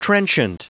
Prononciation du mot trenchant en anglais (fichier audio)
Prononciation du mot : trenchant